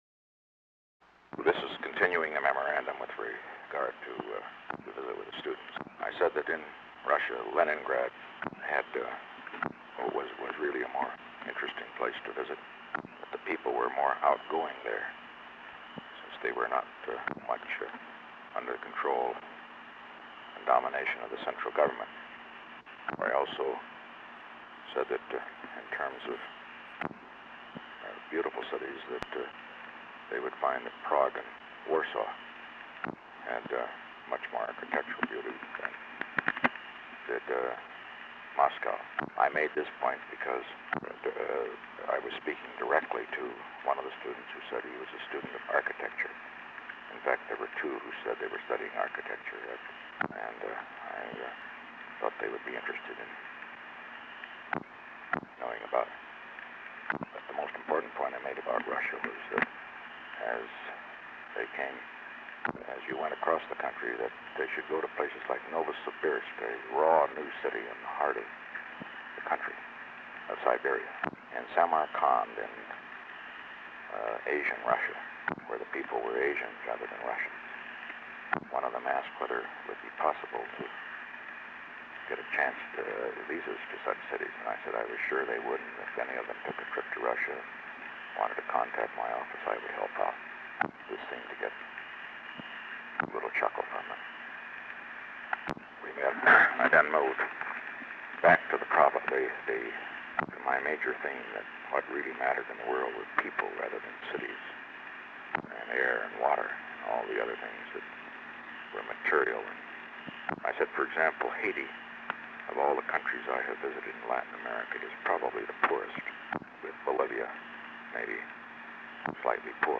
Segment 1 Subject: Dictation of a memo by the President to Haldeman regarding the President’s visit with college students at the Lincoln Memorial [Continued from DB 076 and DB 079 and continued as DB 080]